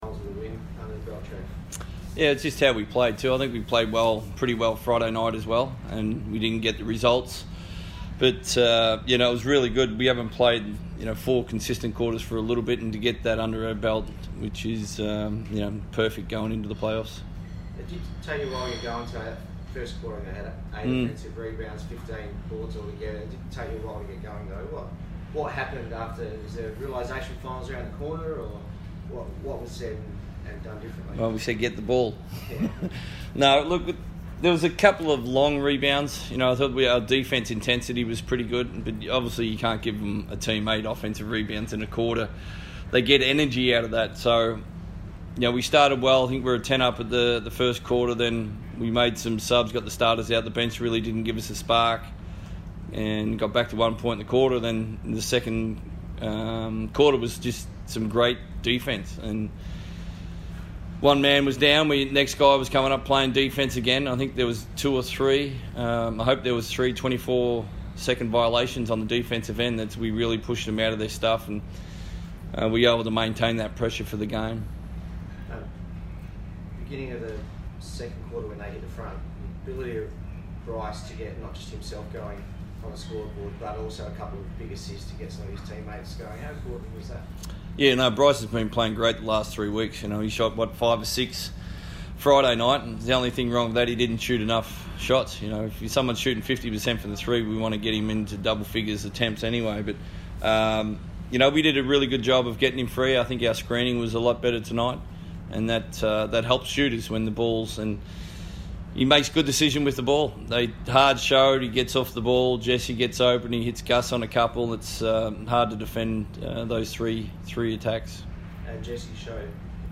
Trevor Gleeson and Damian Martin Press Conference - 18 February 2018